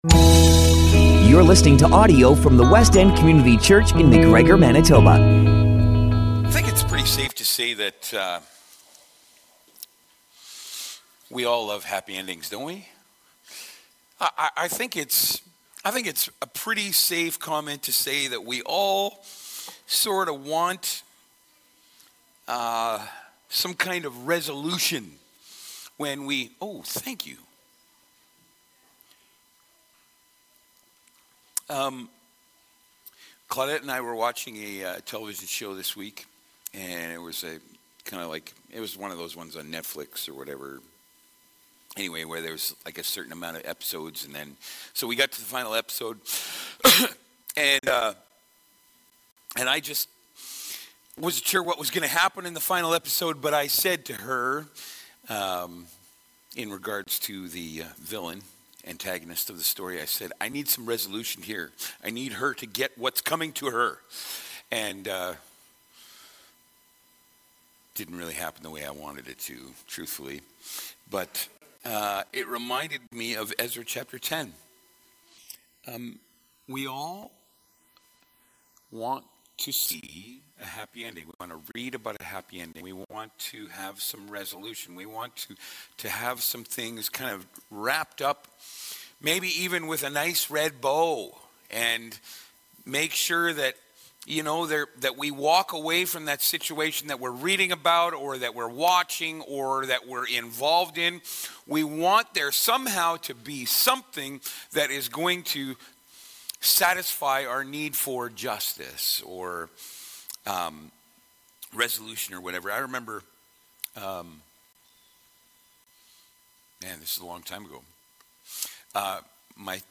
Sermons - Westend Community Church